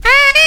SAXXY.wav